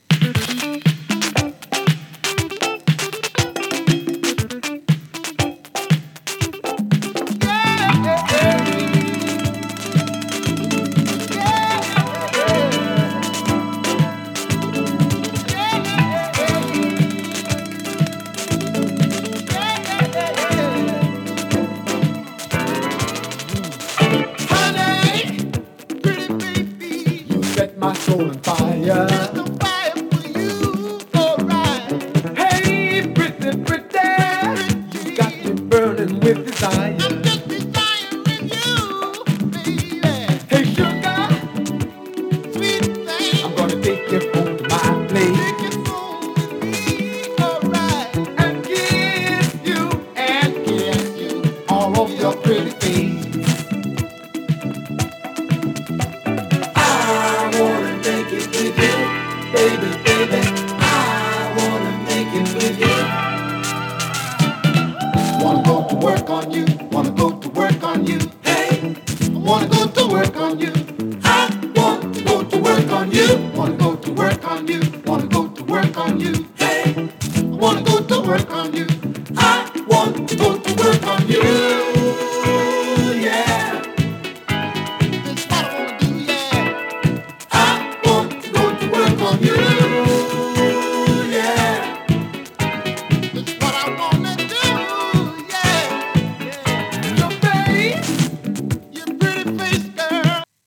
A面は跳ねるリズムが印象的なブルーアイド系ミディアム。
[2track 12inch]＊稀に軽いパチ・ノイズ。